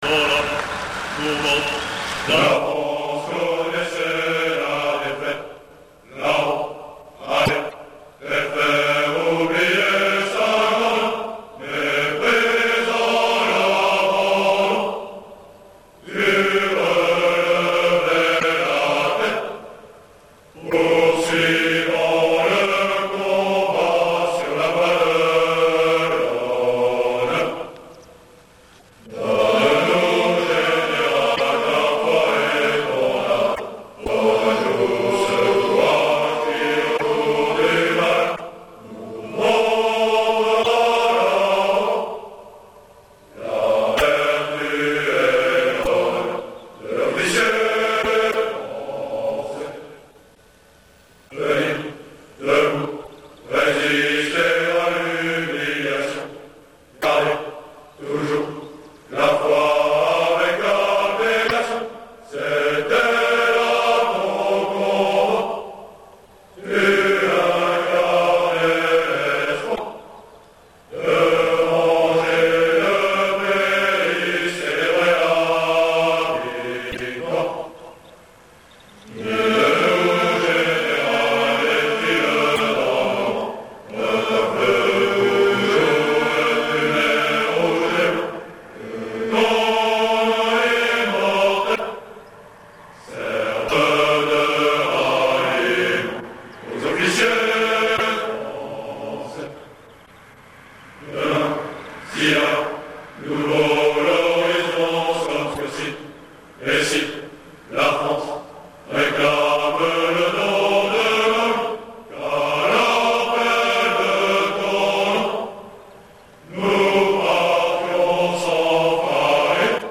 Le chant promo